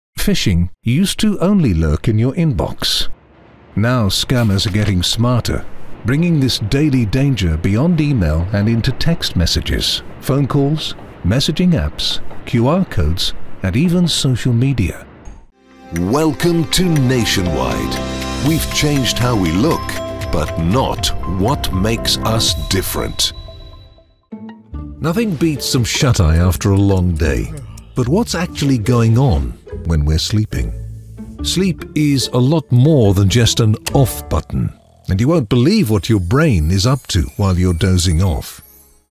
Anglais (britannique)
Démo commerciale
Narration
Aston Spirit microphone
Fully acoustic/sound treated recording environment